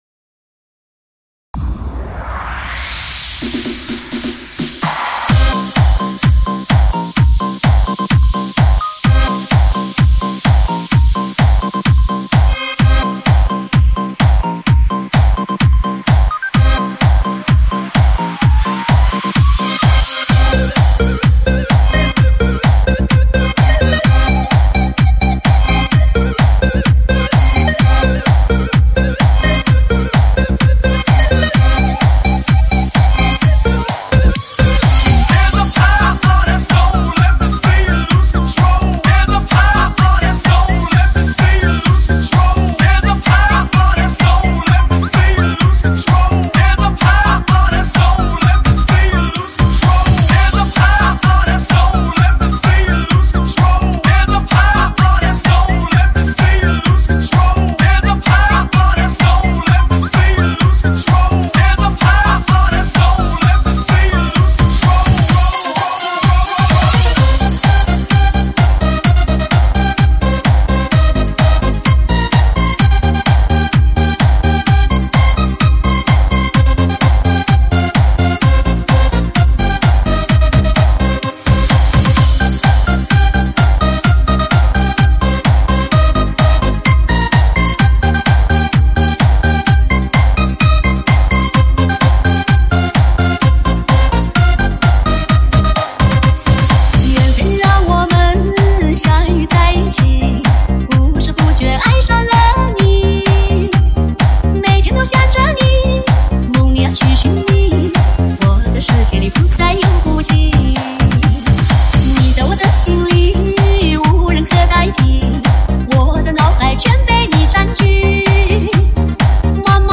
火辣中文嗨曲
全球限量版 神曲劲摇1号 爽歪歪最嗨重音中文
魅力舞曲的强力比拼 经典的发烧舞曲超炫到底
够嗨够疯狂给你的听觉增添新的一种感受